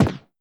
player-kicked.wav